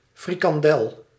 A frikandel (Dutch pronunciation: [frikɑnˈdɛl]
Nl-frikandel.ogg.mp3